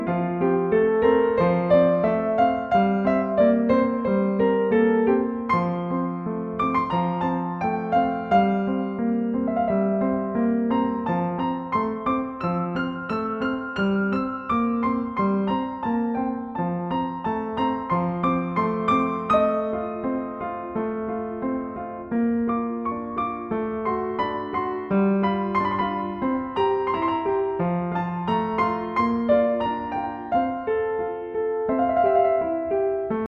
例えば２楽章の終盤。右と左の動きが微妙に異なる上端と、右のリズムが遊ぶように変化する下段。
とはいえ左手は淡々と伴奏的な１６分音符を奏で続けます。